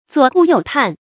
注音：ㄗㄨㄛˇ ㄍㄨˋ ㄧㄡˋ ㄆㄢˋ
左顧右盼的讀法